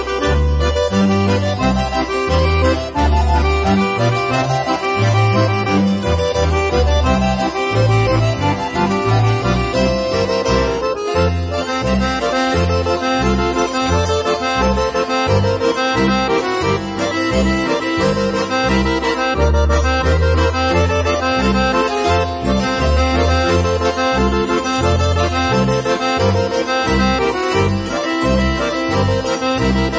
Jodellieder, Naturjodel, Ratzliedli